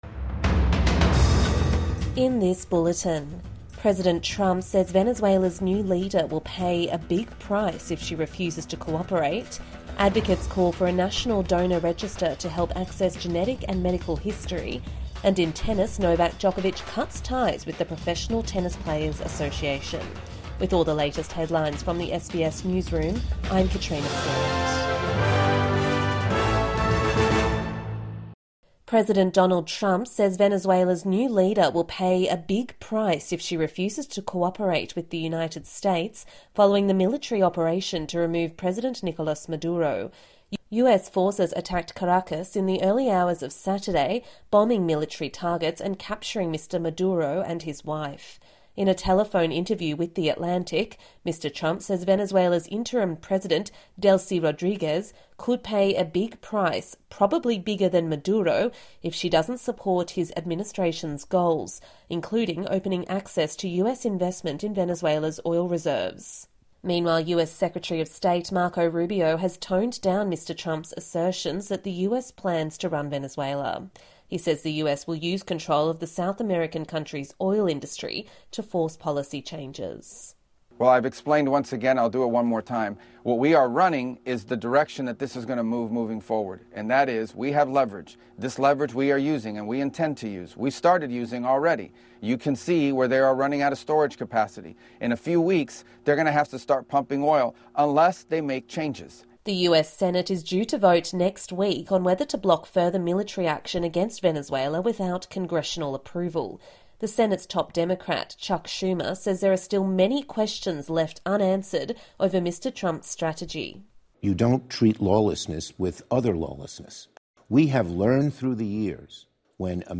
President Trump warns Venezuela's new leader must cooperate | Midday News Bulletin 5 January 2026